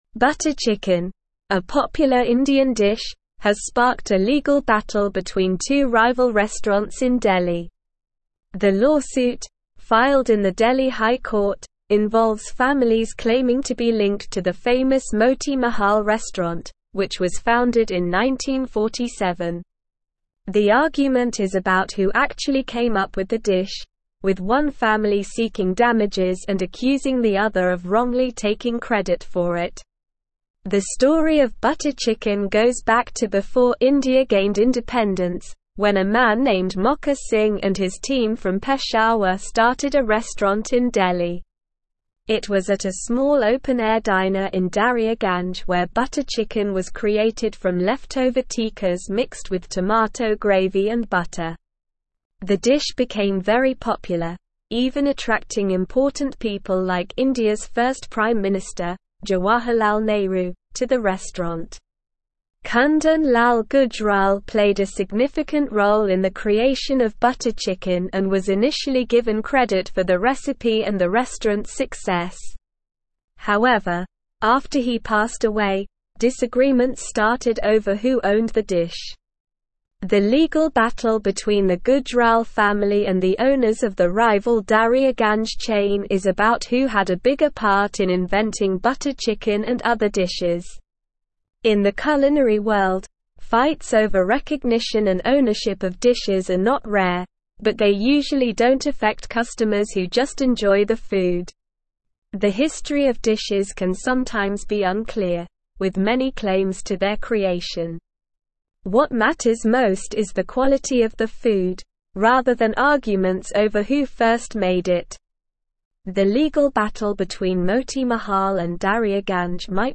Slow
English-Newsroom-Upper-Intermediate-SLOW-Reading-Butter-Chicken-Origins-Delhi-Restaurants-in-Legal-Battle.mp3